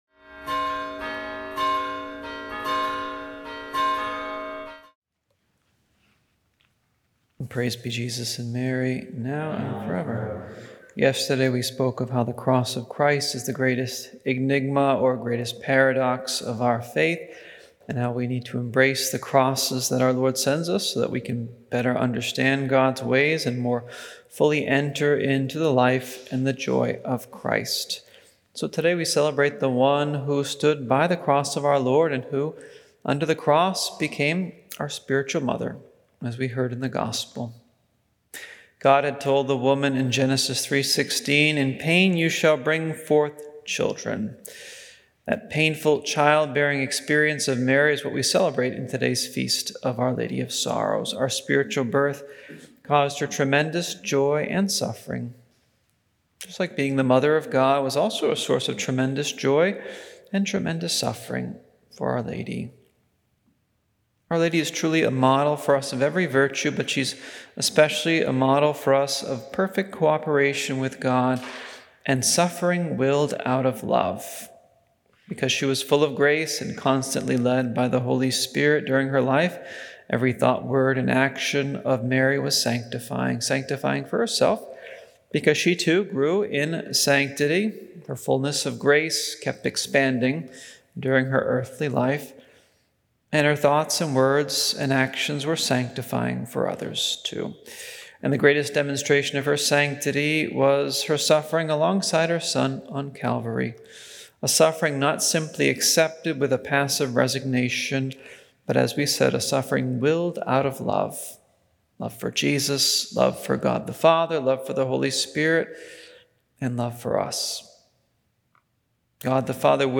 Our Lady of Sorrows: A Feast of Suffering- Sep 15 – Homily